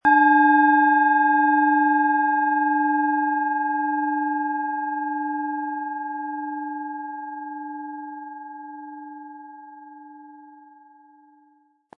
Planetenschale® Intuition stärken & Inspiriert fühlen mit Neptun, Ø 11,9 cm, 320-400 Gramm inkl. Klöppel
Planetenton 1
Um den Original-Klang genau dieser Schale zu hören, lassen Sie bitte den hinterlegten Sound abspielen.
Durch die überlieferte Fertigung hat sie dafür diesen besonderen Spirit und eine Klangschwingung, die unser Innerstes berührt.
SchalenformBihar
MaterialBronze